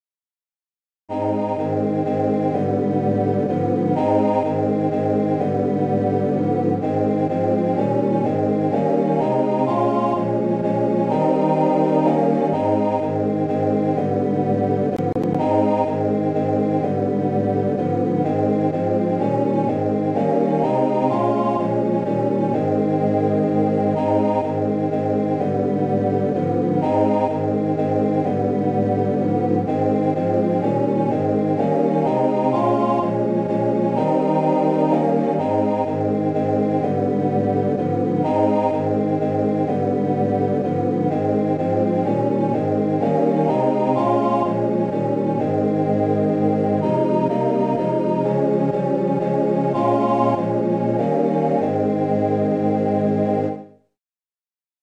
Written in men's 4-part harmony.